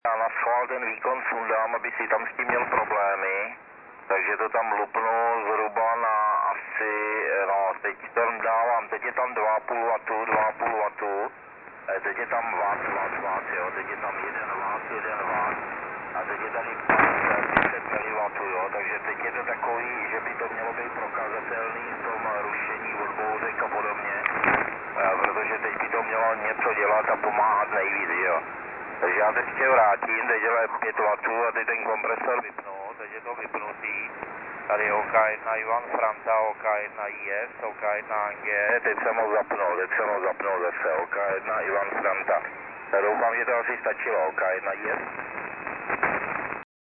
Samozřejmě, že pro QRP je výhodnější používat CW a digitální druhy provozu, než SSB.